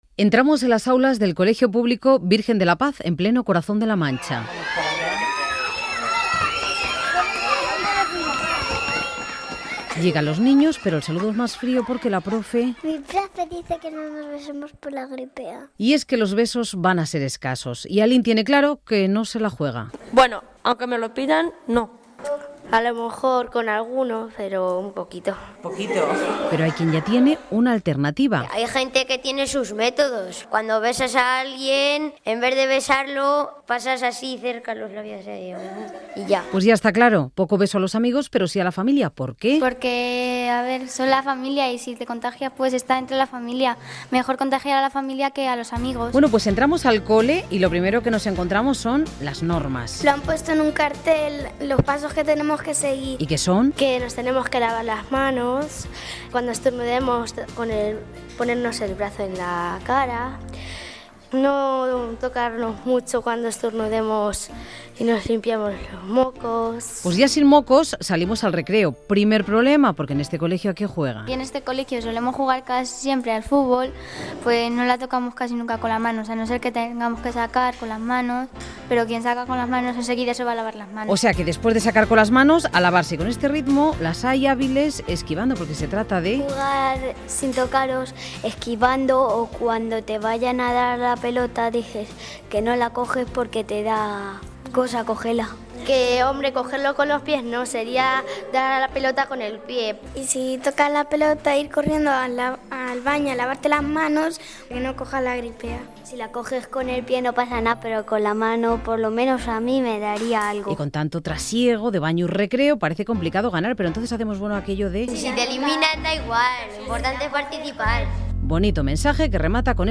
Reportatge sobre la Grip A i com es viu en una escola per evitar encomanar-se'n
Informatiu